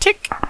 Adding some sound effects
mach_pew.ogg